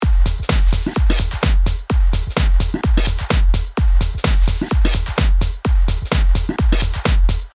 • Tube ：真空管を通したときのような暖かみを持った歪みが加わる
ドライブレベル50％：オフの時よりも少し倍音が多めに聞こえて来ましたかね？